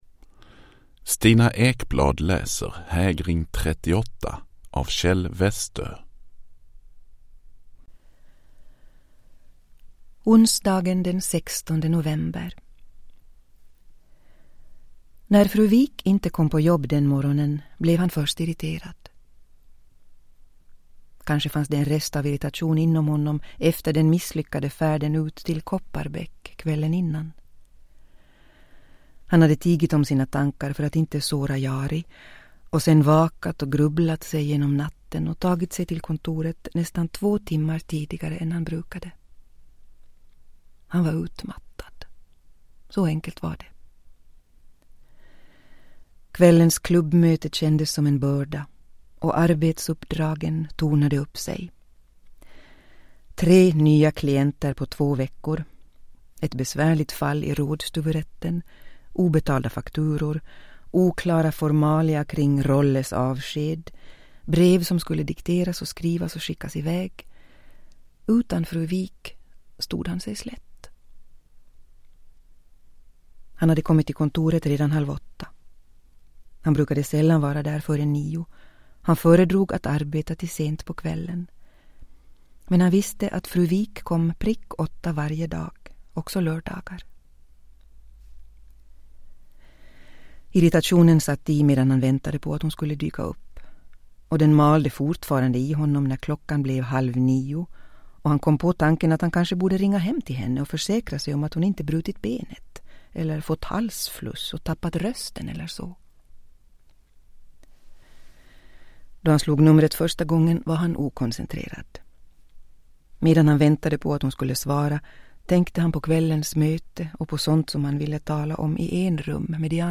Uppläsare: Stina Ekblad
Ljudbok